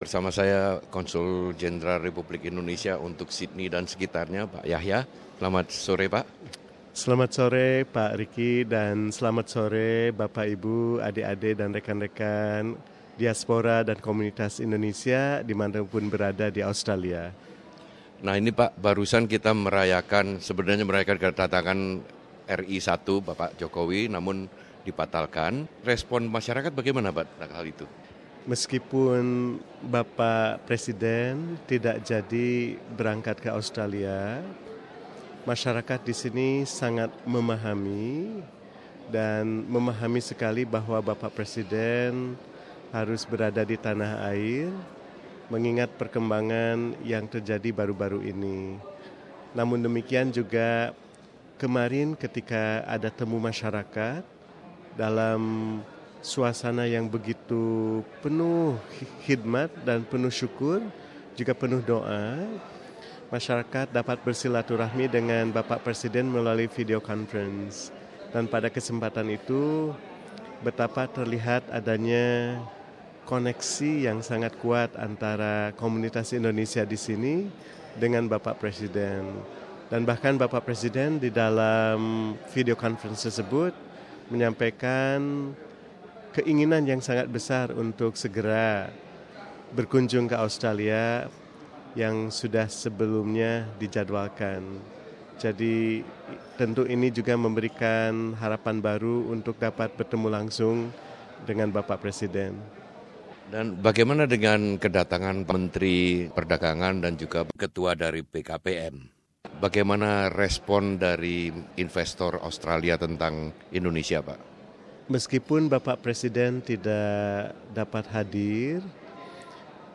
Wawancara dengan Konsul Jenderal RI untuk wilayah NSW dan sekitarnya Yayan G. Mulyana mengenai penundaan kedatangan presiden RI Joko Widodo dan mengenai forum perdagangan bisnis yang dimeriahkan dengan kedatangan Menteri Perdagangan Enggartiasto dan Kepala BKPM Tom Lembong.